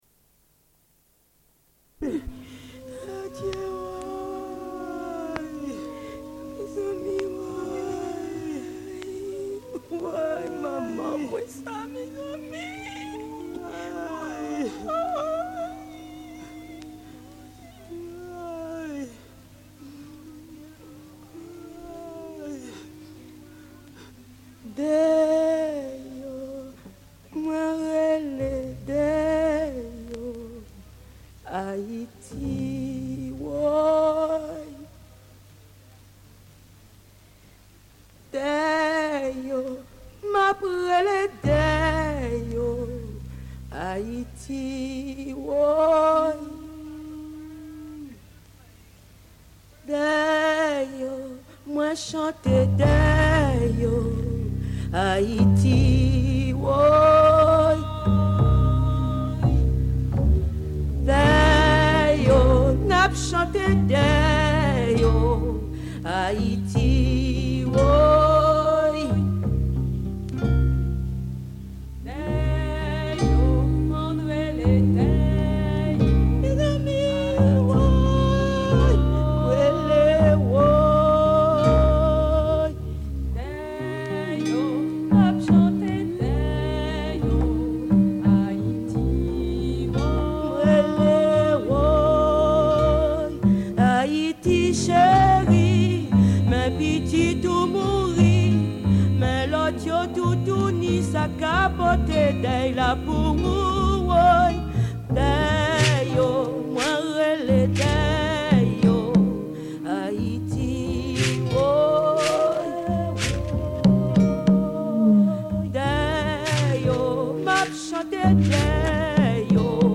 Écoute d'une émission de radio (en créole) produite par les femmes du Muvman.